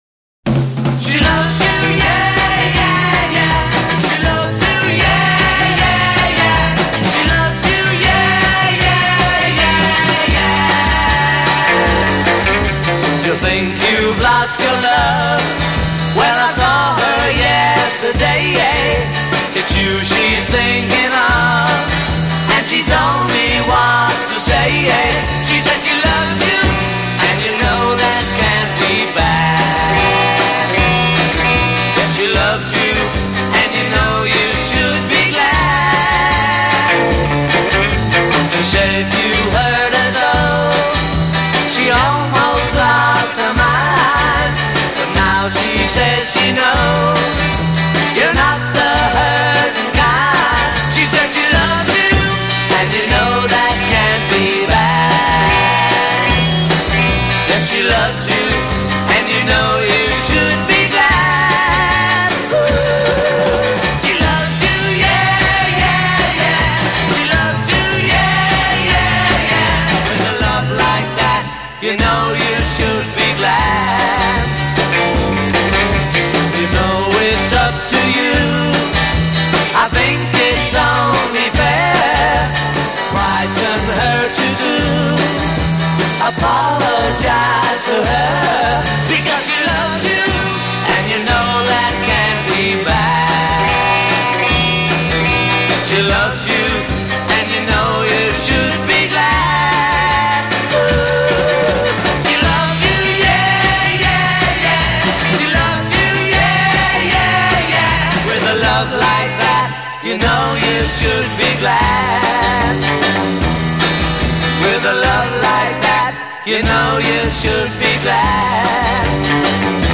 voice & rhythm guitar
voice & bass guitar
lead guitar
drums
A1 chorus 1 0:00 8 unison singing with 6th chord at end a1
B verse 0: 8 unison duet with harmony at end of line b